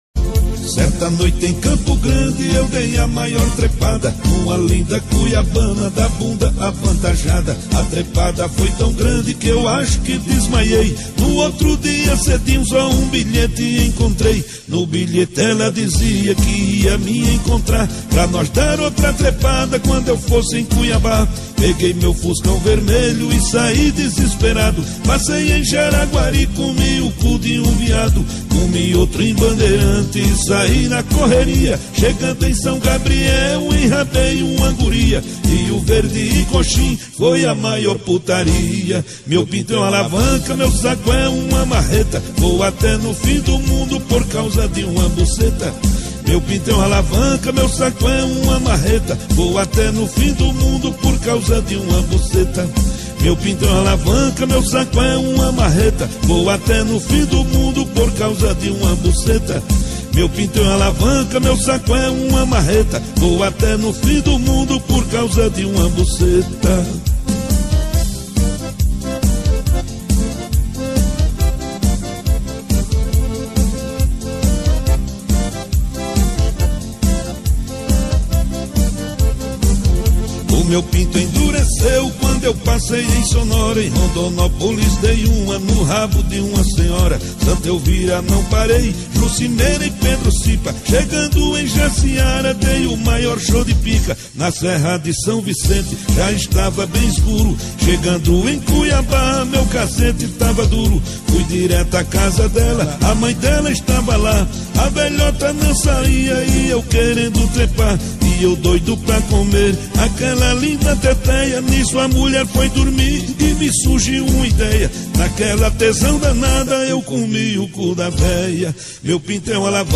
2024-03-01 22:55:45 Gênero: MPB Views